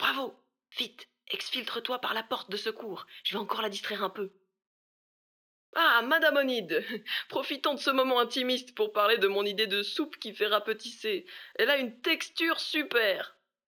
VO_LVL3_EVENT_Bravo reussite mission_01.ogg